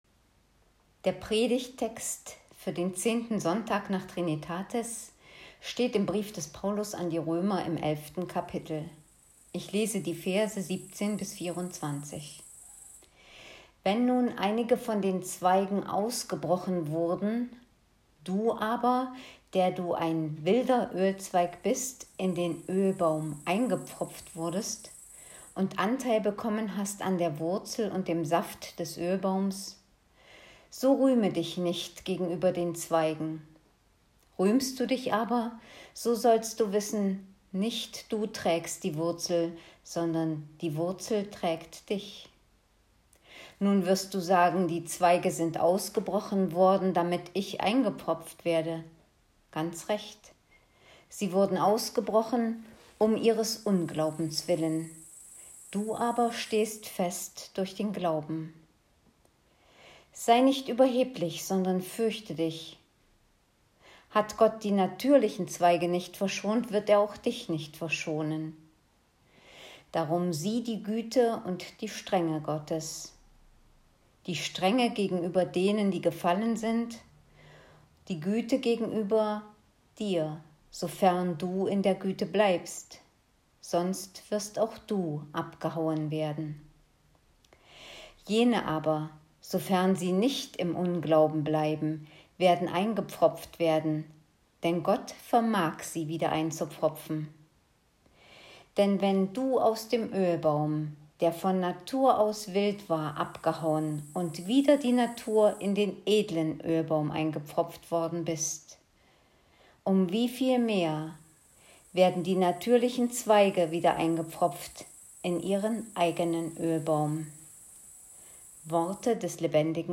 Predigt vom 16.08.2020 - Kirchgemeinde Pölzig